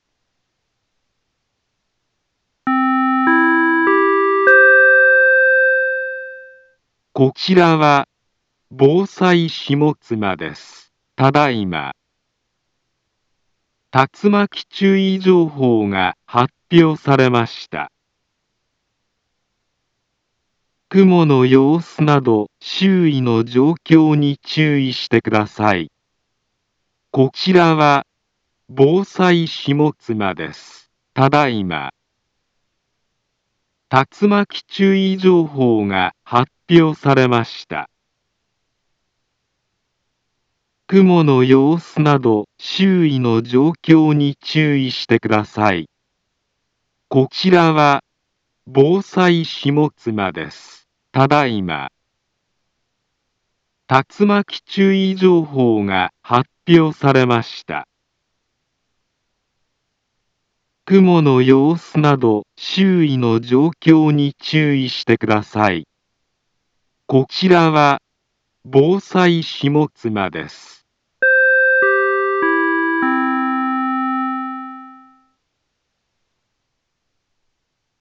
Back Home Ｊアラート情報 音声放送 再生 災害情報 カテゴリ：J-ALERT 登録日時：2025-07-10 17:04:37 インフォメーション：茨城県南部は、竜巻などの激しい突風が発生しやすい気象状況になっています。